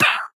Minecraft Version Minecraft Version snapshot Latest Release | Latest Snapshot snapshot / assets / minecraft / sounds / mob / parrot / death2.ogg Compare With Compare With Latest Release | Latest Snapshot